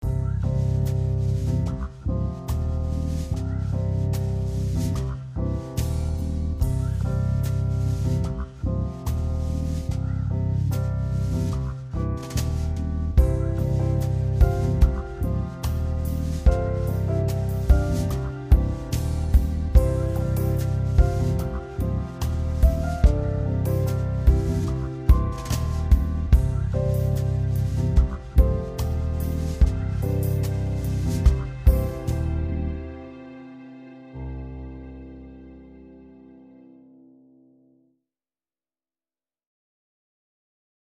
apaisante - cool - tranquille - serenite - calme